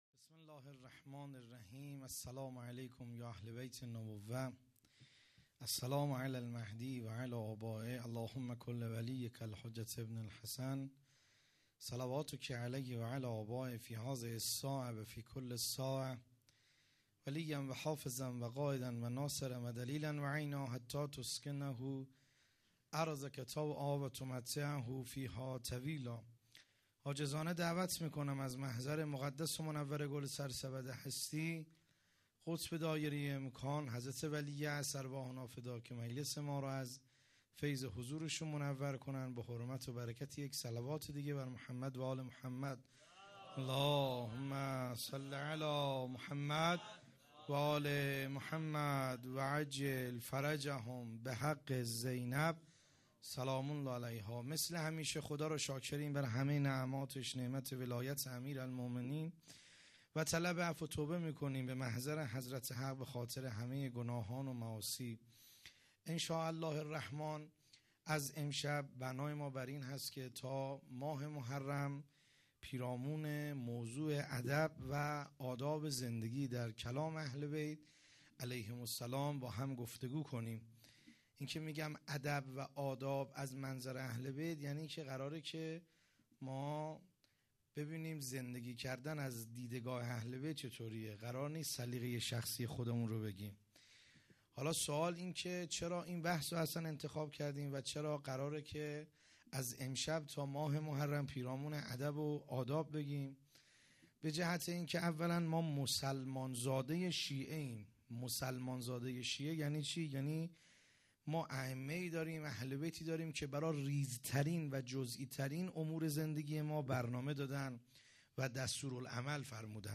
سخنرانی
جلسه هفتگی ( ۱۱ تیرماه)